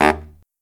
LOHITSAX15-R.wav